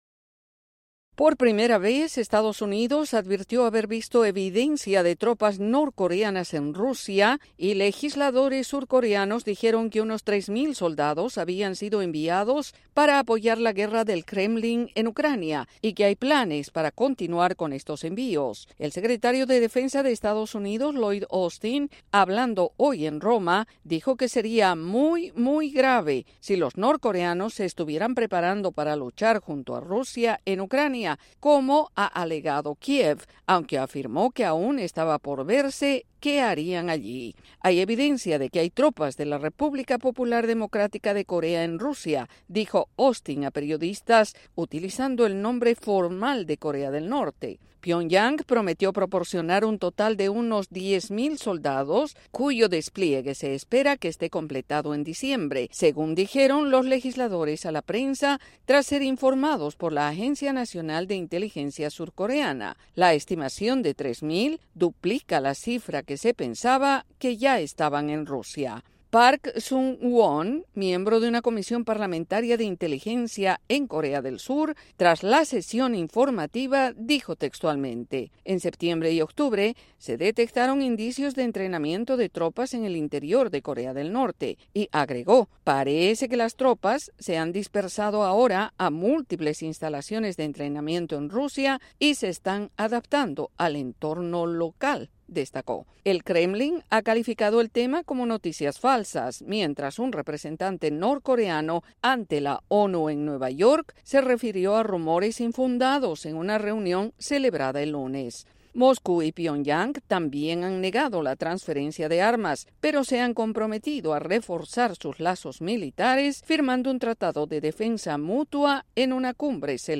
Las advertencias sobre la presencia de tropas norcoreanas en Rusia despiertan alarma en EEUU y sus aliados por el riesgo que significa para Ucrania. El informe